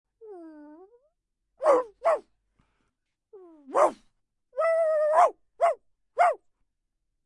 Dog Barking